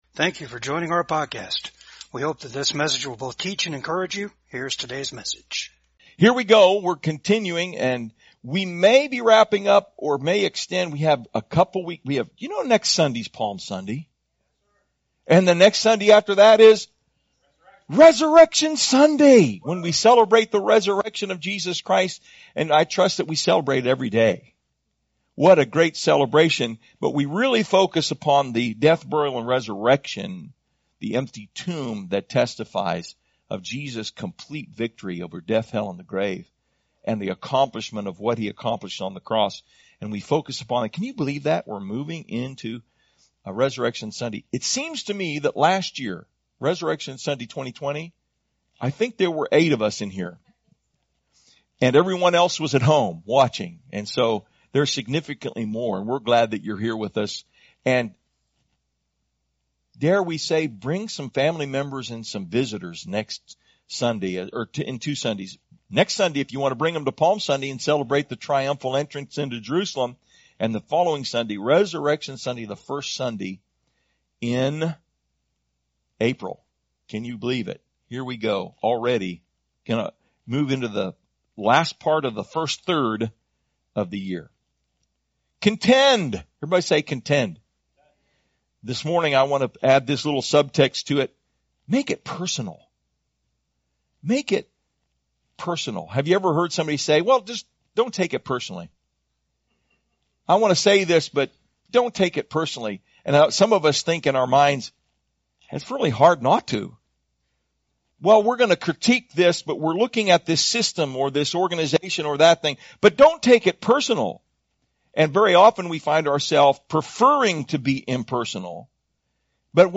Luke 10 Service Type: VCAG SUNDAY SERVICE Jesus is focused upon making this personal for us.